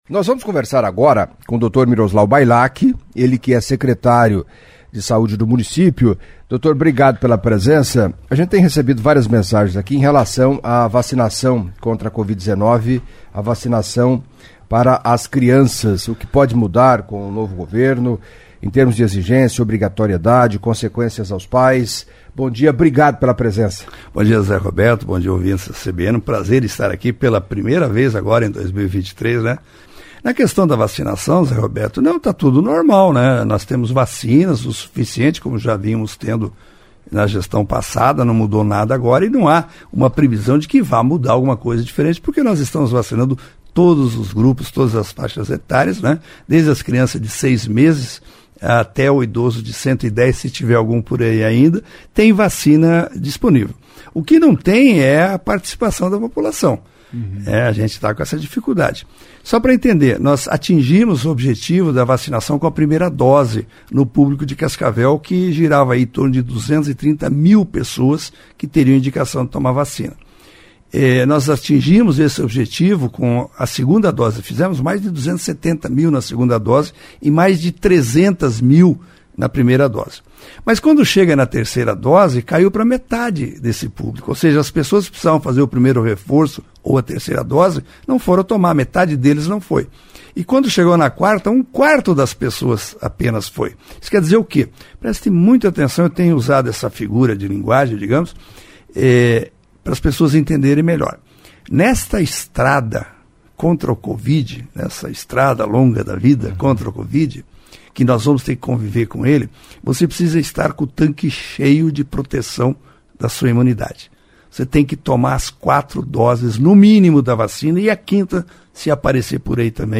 Em entrevista à CBN Cascavel nesta sexta-feira (13) o secretário de Saúde do município, Miroslau Bailak, falou das ações da pasta previstas para 2023 e respondeu dúvidas de ouvintes. Entre as perguntas, está sobre uma possível obrigatoriedade de tomar a vacina contra Covid-19.